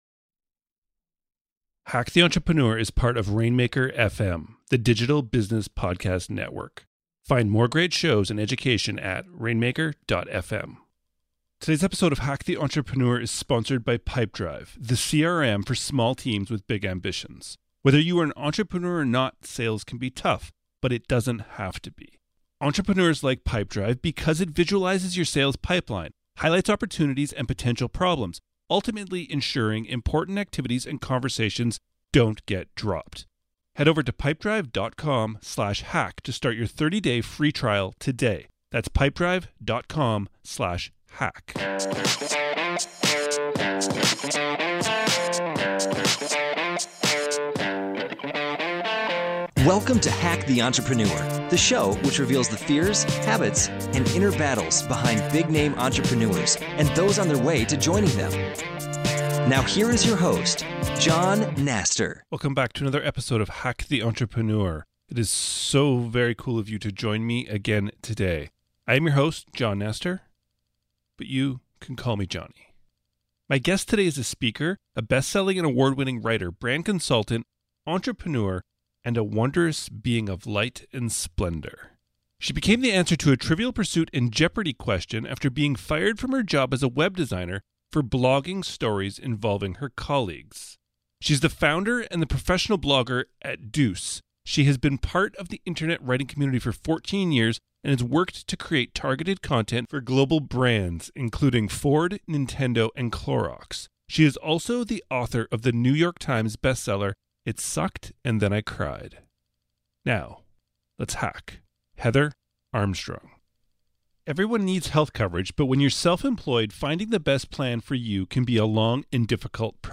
My guest today is a speaker, a bestselling and award-winning writer, brand consultant, and an entrepreneur.